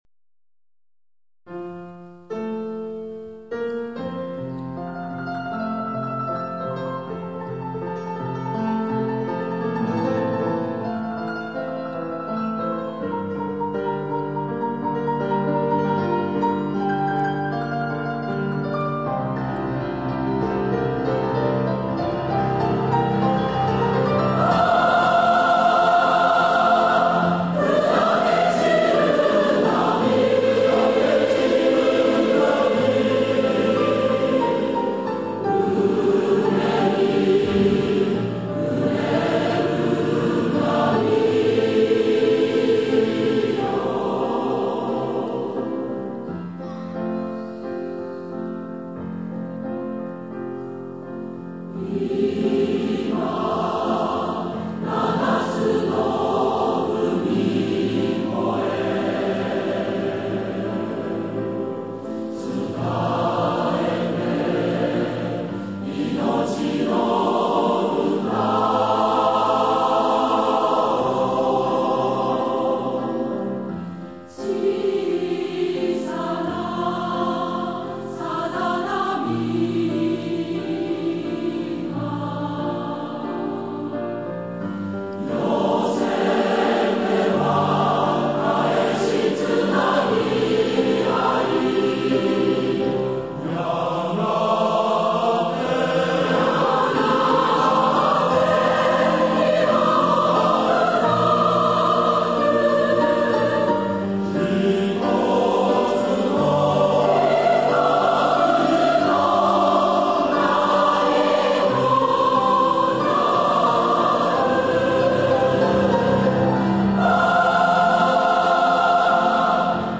演奏：九州のうたごえ合同合唱団（２００３年九州のうたごえ大分祭典での録音）